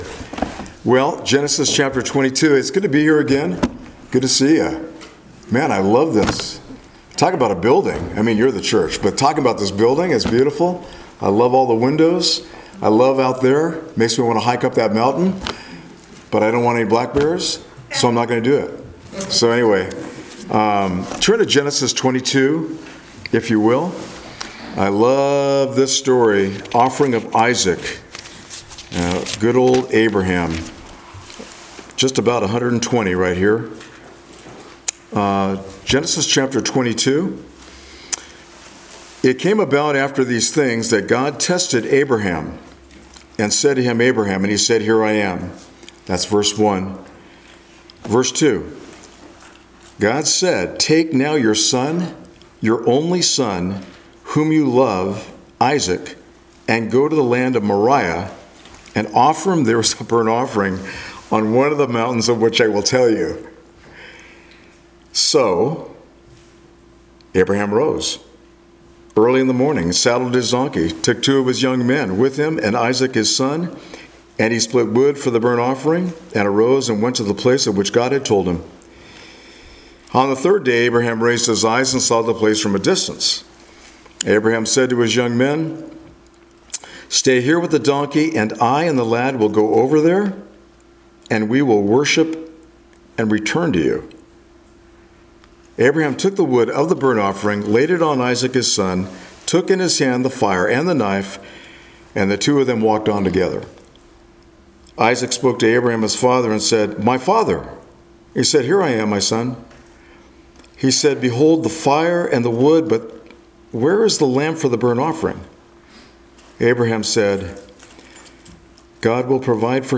July 31, 2022 “Tested But Not Broken” Passage: Genesis 22:1-14 Service Type: Sunday Morning Worship The Sacrifice of Isaac 22 After these things God tested Abraham and said to him, “Abraham!”